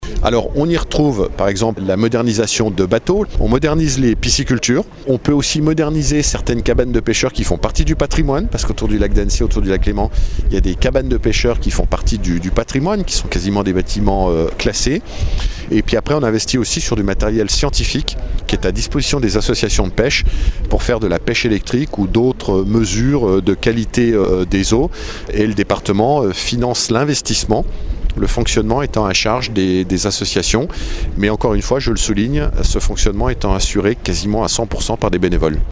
Alors que retrouve-t-on aujourd’hui dans ce plan pêche, on fait le point avec Martial Saddier président du conseil départemental de Haute-Savoie Télécharger le podcast Partager :